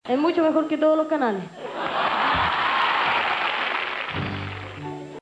es-mucho-mejor-que-los-otros-canales-y-risas.mp3